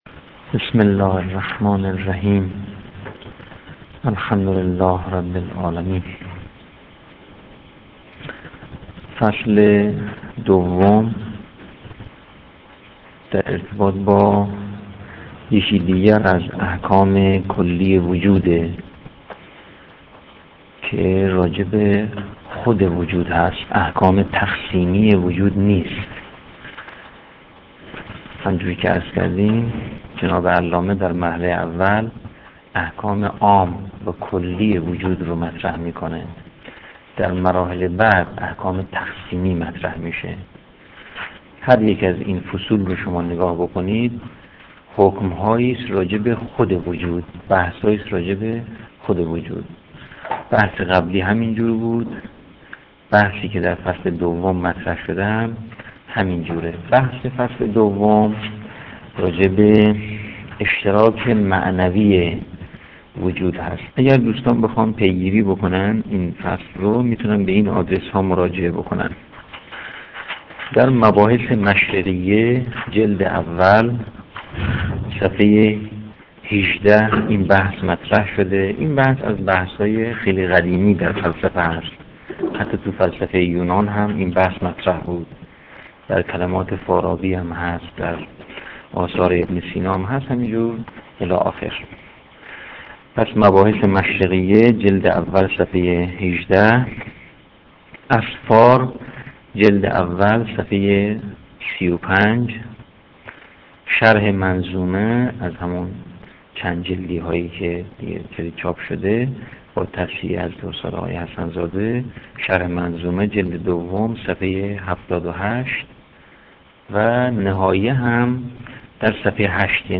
تدریس بدایه الحکمه